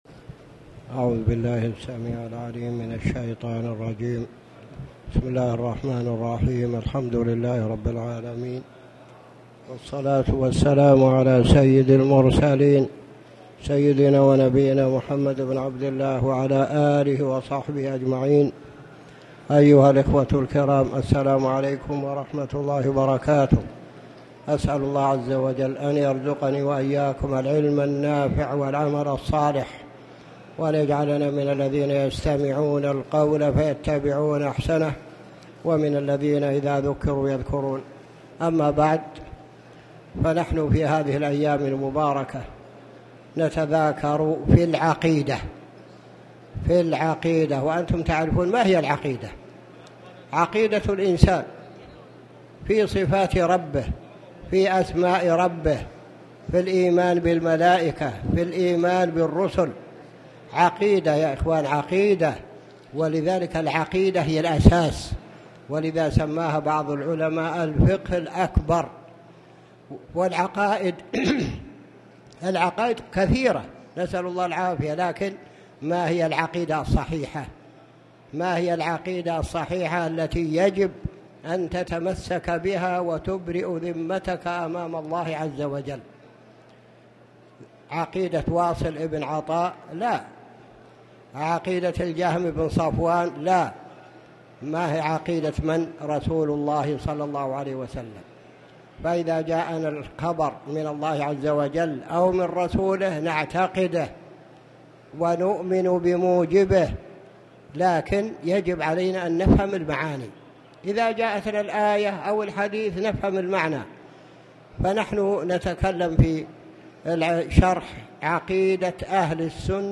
تاريخ النشر ٢١ شعبان ١٤٣٨ هـ المكان: المسجد الحرام الشيخ